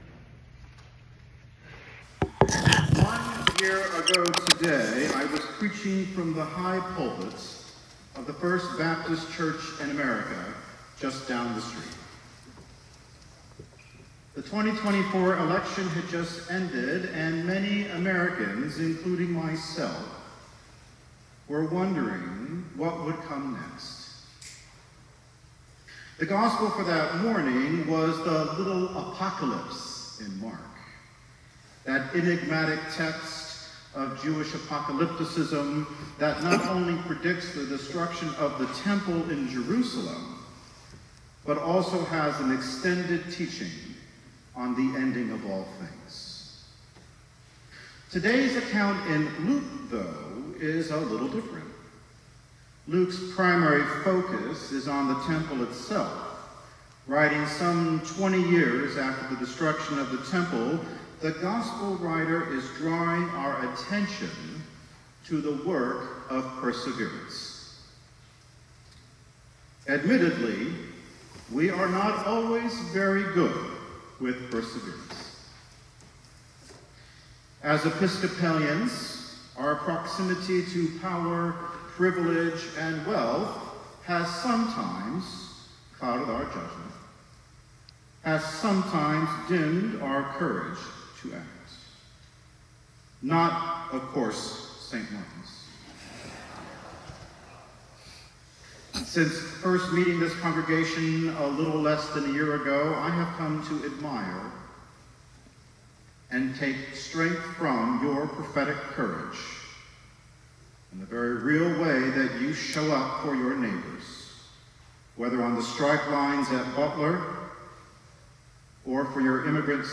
Sermon Recording